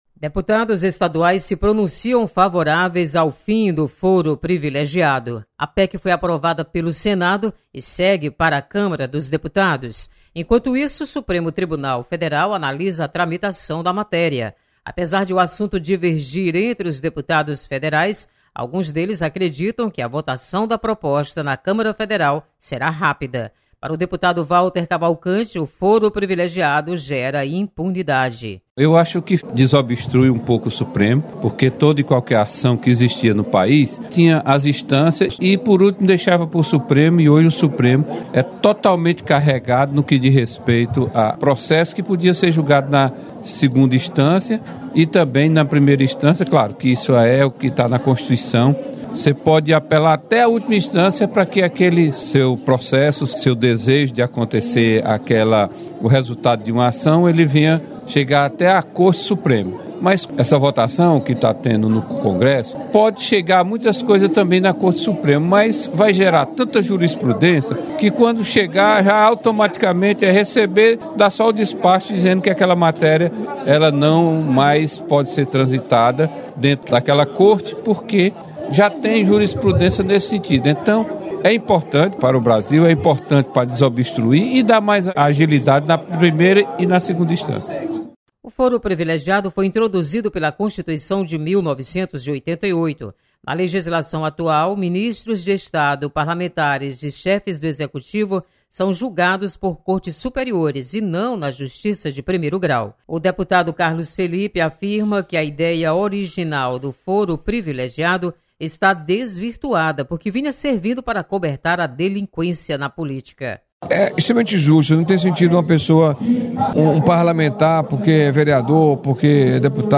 Foro Privilegiado é tema de debate no Plenário 13 de Maio. Repórter